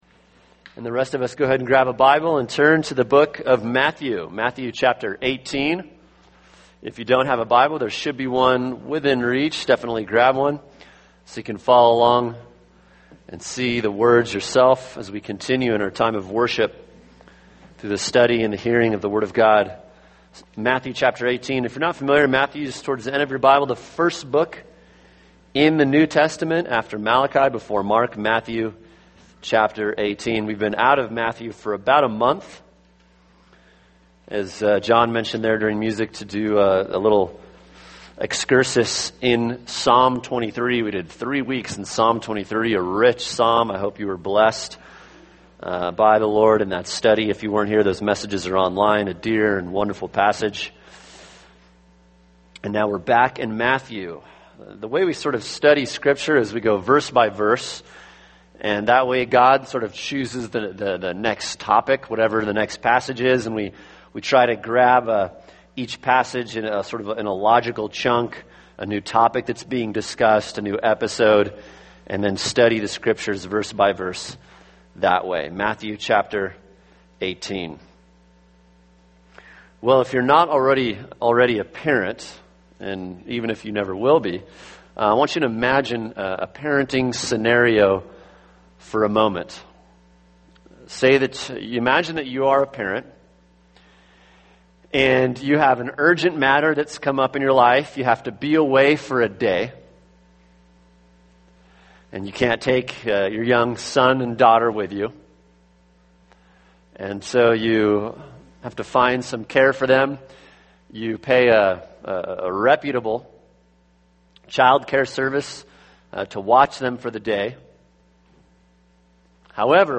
[sermon] Matthew 18:5-9 Sharing God’s Concern for Holiness | Cornerstone Church - Jackson Hole